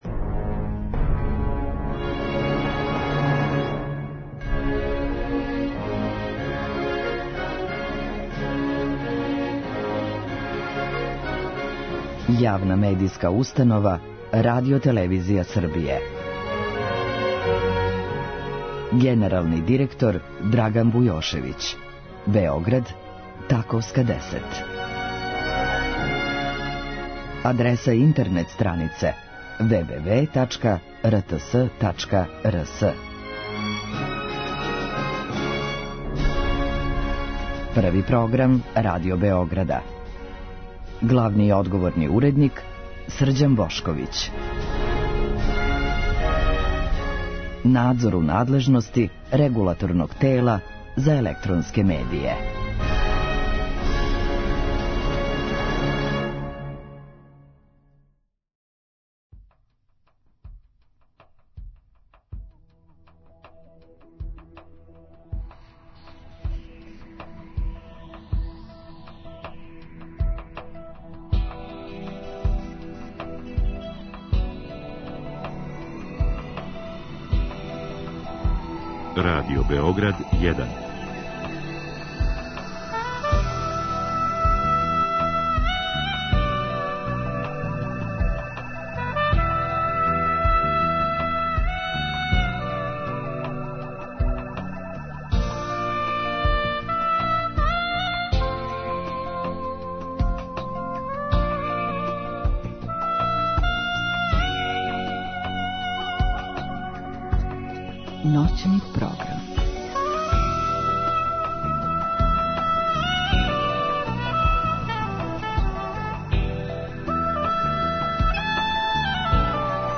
У трећем и четвртом сату слушаћемо извођење баса Мирослава Чангаловића и то соло песама Модеста Мусоргског.